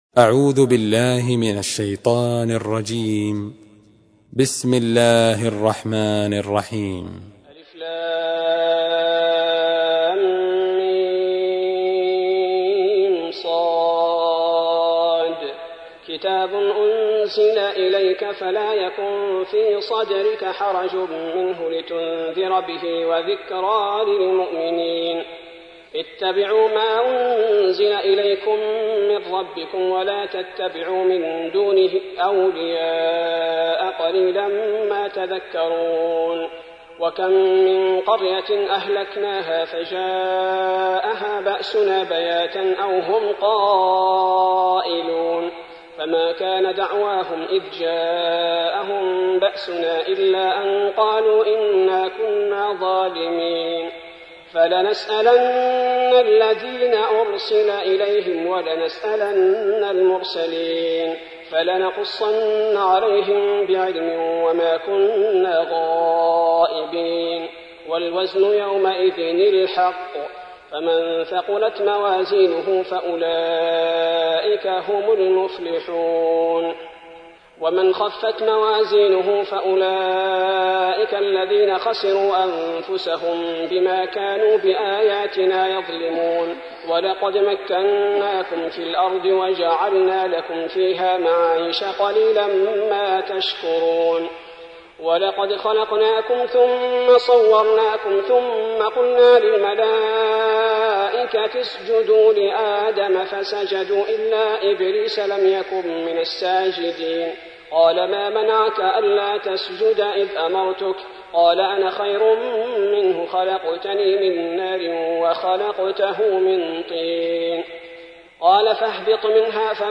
تحميل : 7. سورة الأعراف / القارئ عبد البارئ الثبيتي / القرآن الكريم / موقع يا حسين